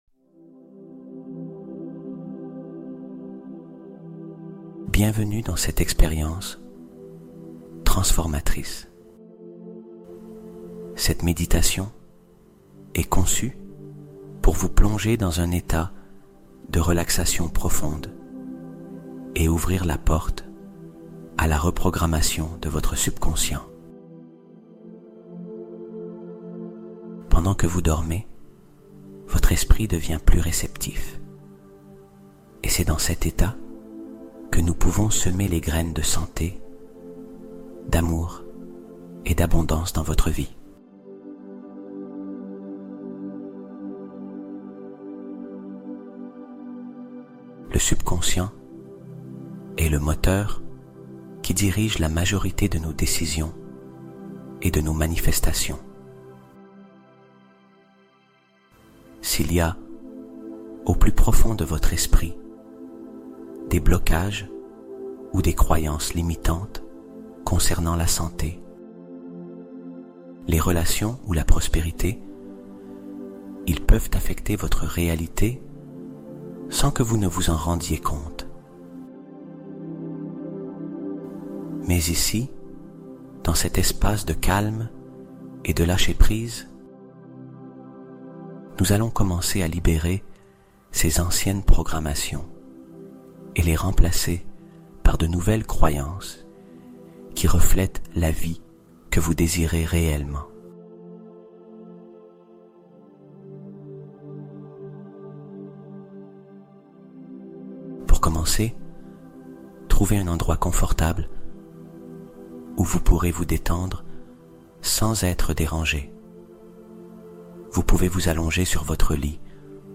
Bien-Être Global : Hypnose nocturne pour la santé et l'harmonie intérieure